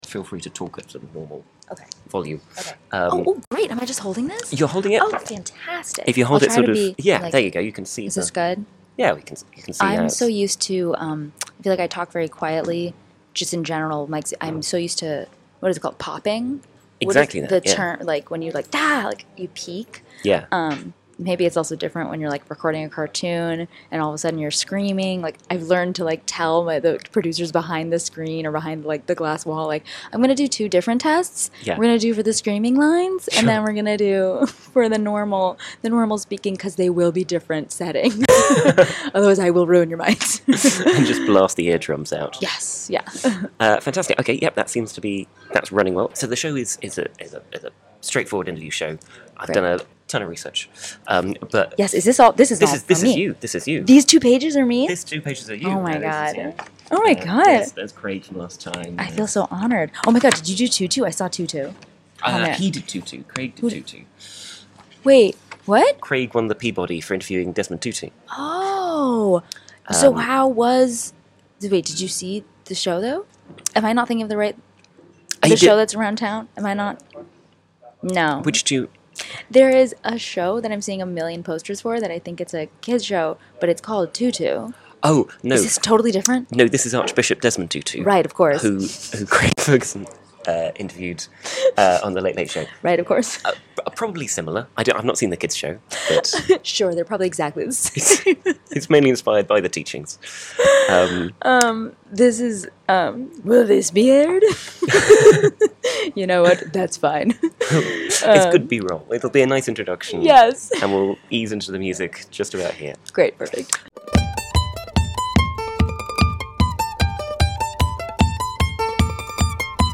Facebook Twitter Headliner Embed Embed Code See more options My guest this episode is Eden Sher, the Critics' Choice Award Winning Star of 'The Middle', on creating her sell-out Edinburgh Fringe show 'I Was On A Sitcom', on learning from her sitcom royalty onscreen parents, being directed by her onscreen brother, being plucked from obscurity as a child by Jay Leno's Tonight Show, and "Brand New Cards". Recorded at the Edinburgh Fringe.